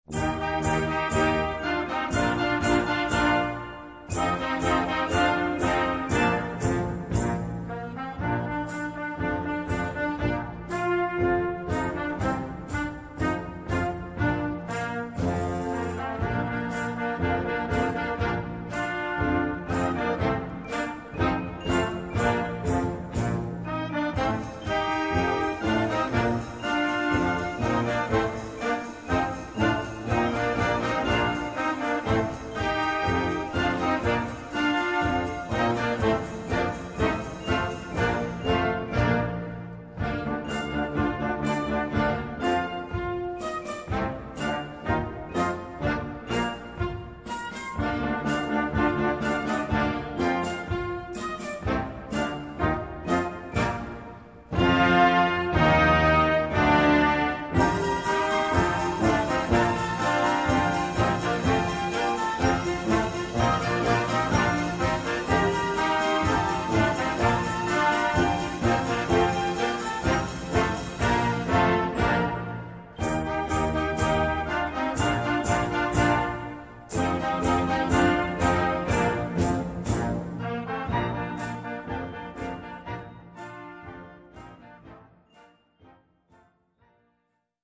Gattung: Italienisches Volkslied
Besetzung: Blasorchester